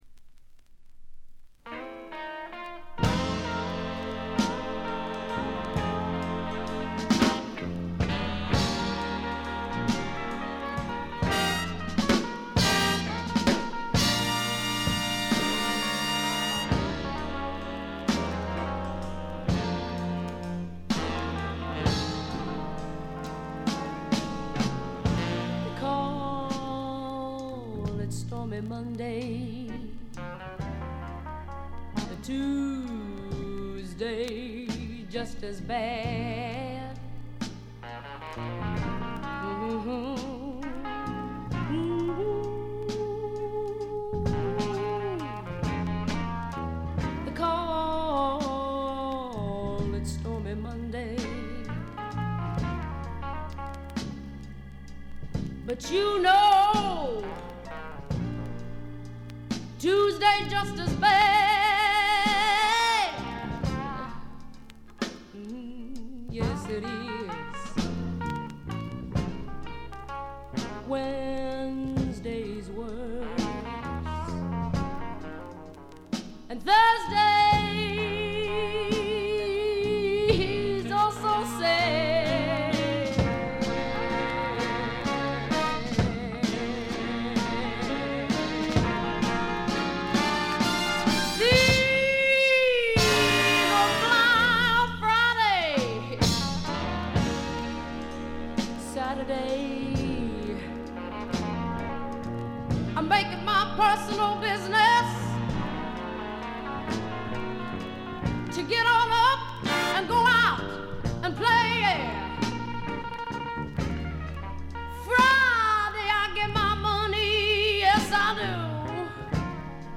鑑賞を妨げるほどのノイズはありません。
試聴曲は現品からの取り込み音源です。